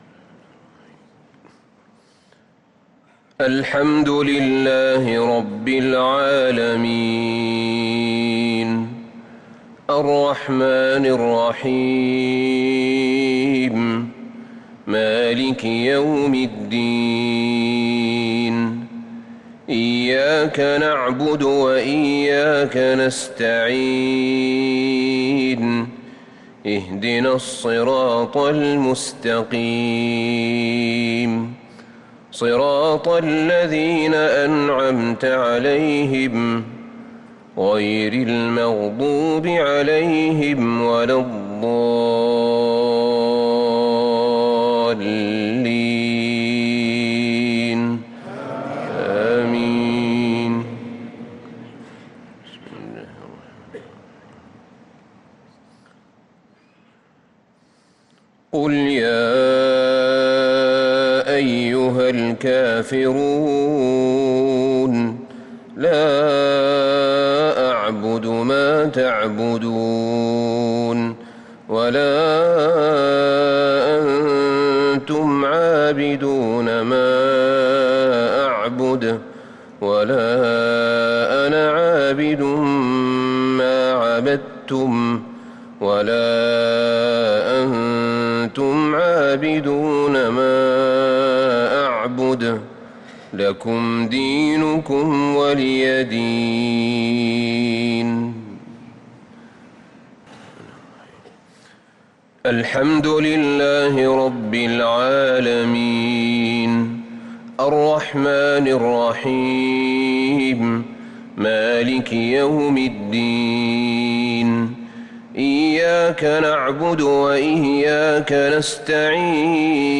صلاة المغرب للقارئ أحمد بن طالب حميد 13 رمضان 1443 هـ